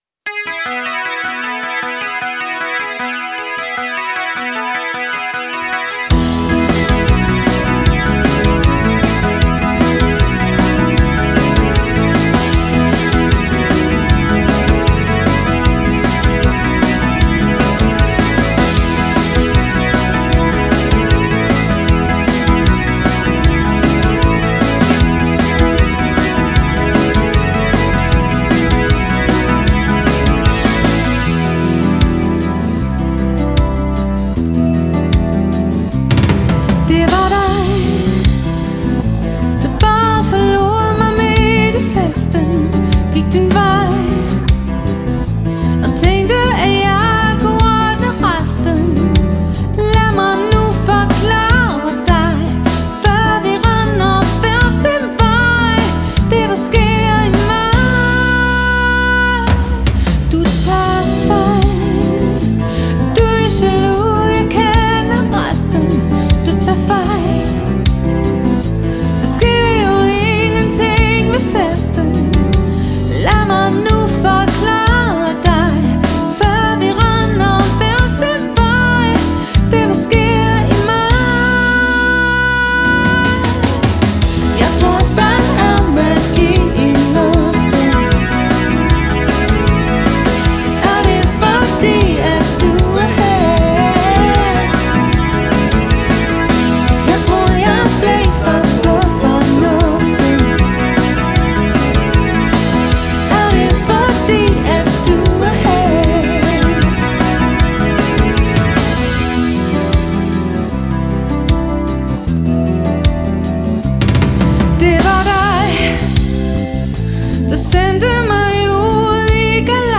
• Allround Partyband
• Coverband
• Duo eller trio